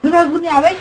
Worms speechbanks
jump2.wav